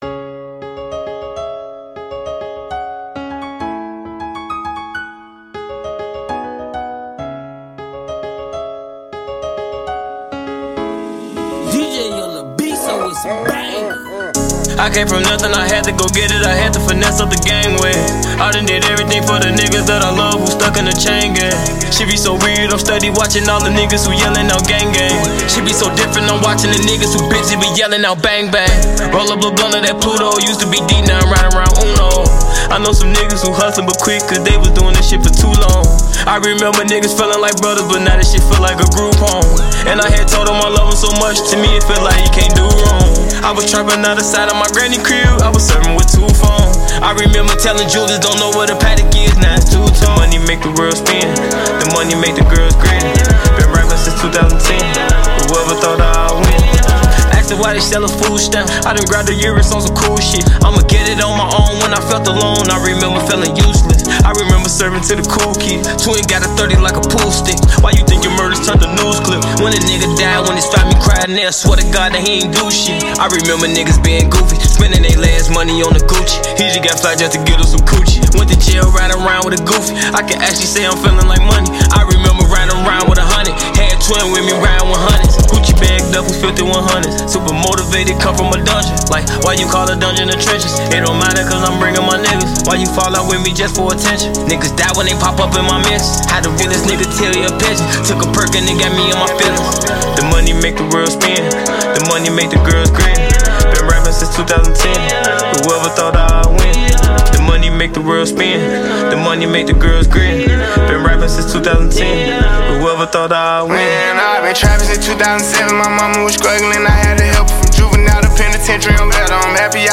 rap Trap hip hop رپ ترپ هیپ هاپ